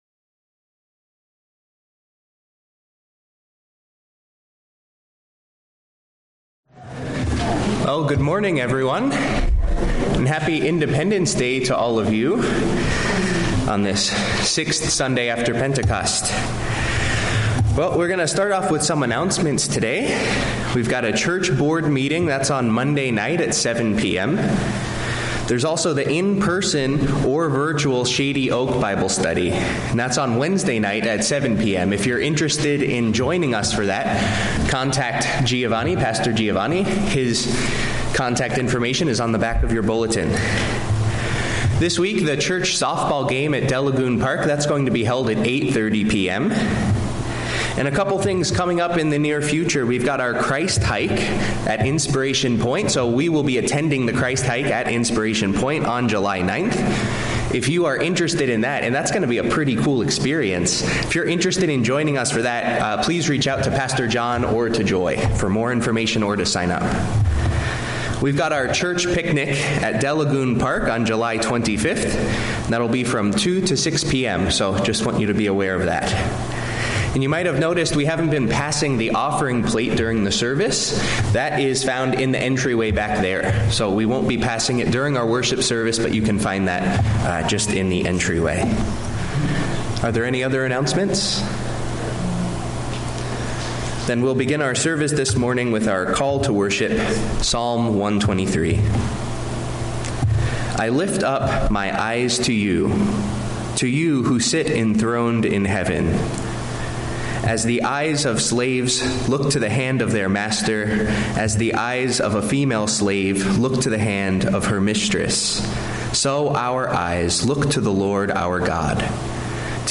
From Series: "Sunday Worship"